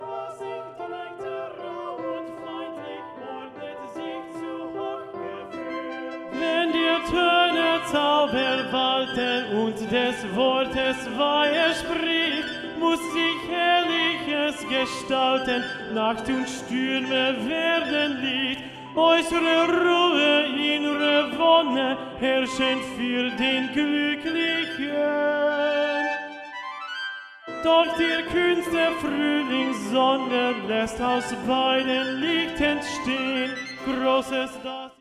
Tenor Vocal Model Recording (all Tenors will audition on Tenor 1, not Tenor 2)